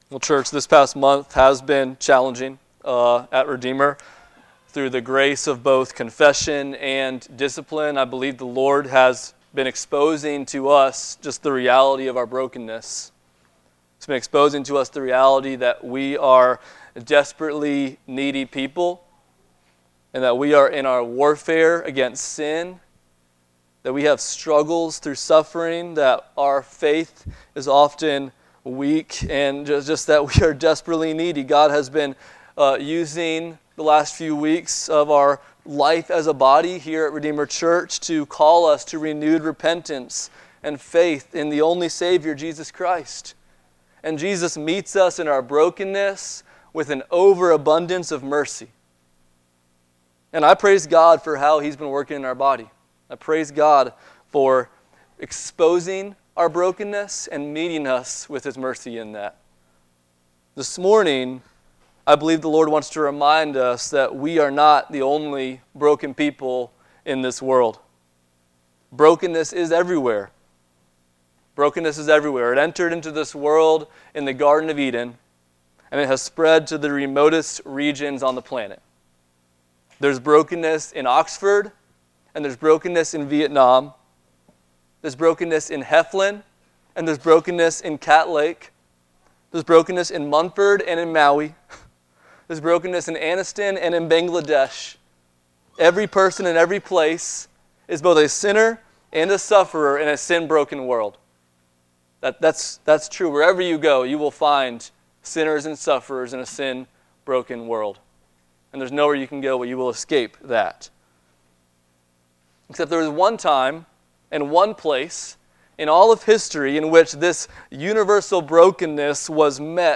Passage: Matthew 15:21-39 Service Type: Sunday Morning